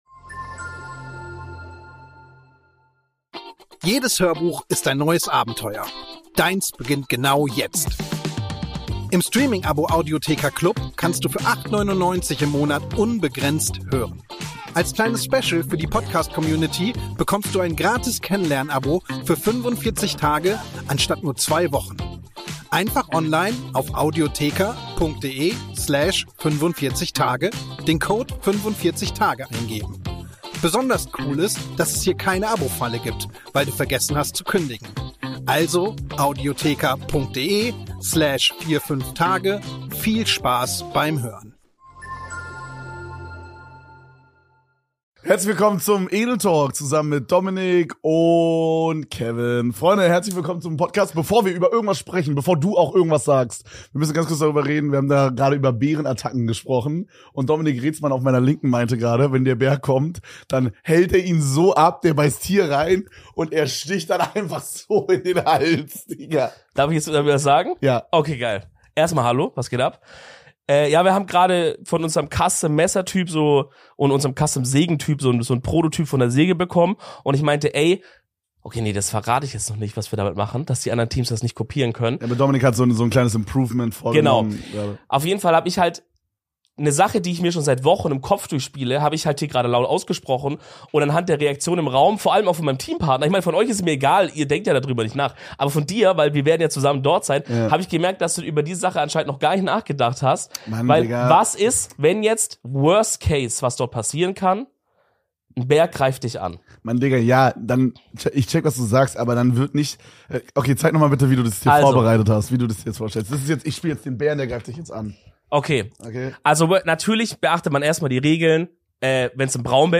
Heute gibts mal wieder eine 2er Folge auf chill, und das sogar in Überlänge.